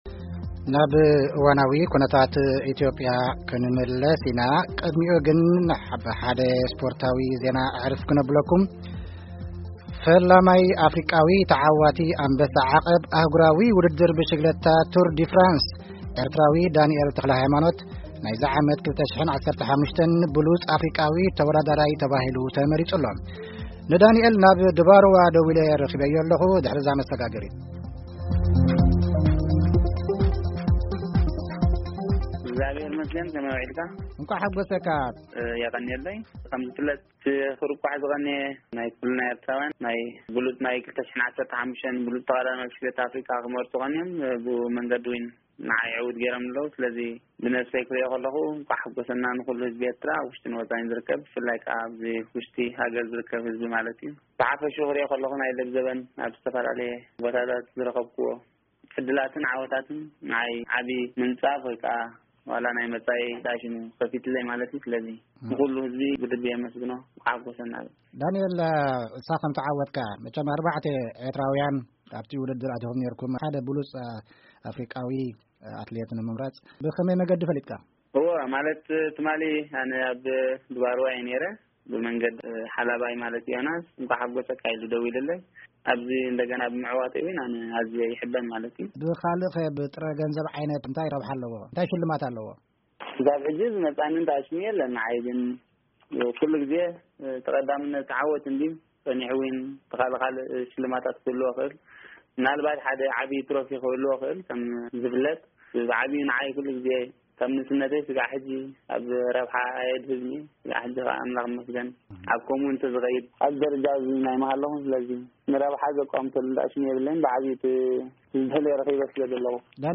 ዳንኤል ተክለሃይማኖት ምስ ዲምጺ አሜሪካ ክፍሊ ትግርኛ ዘካየዶ ቃለ-መጠይቕ ኣብ`ዚ ምስማዕ ይክኣል።